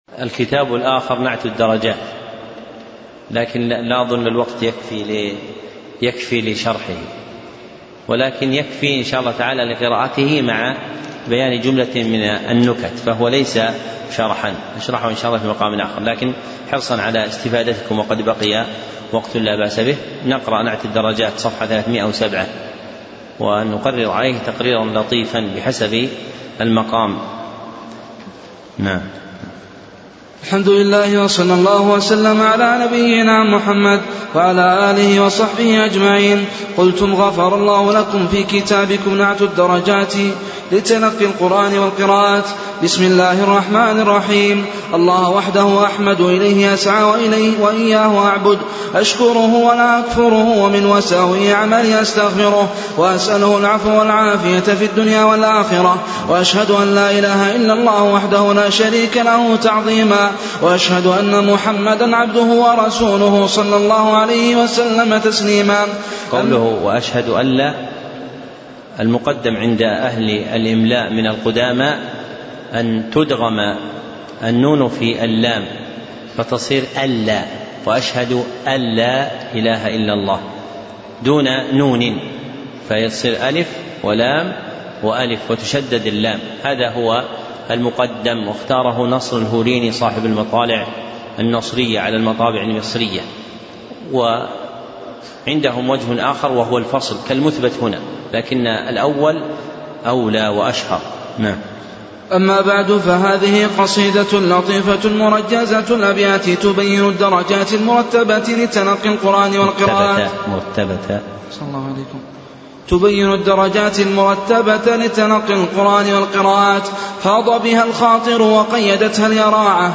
محاضرة صوتية نافعة، وفيها شرح لمنظومة نعت الدرجات لتلق